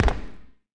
Sfx Dodgeball Bounce Sound Effect
sfx-dodgeball-bounce-3.mp3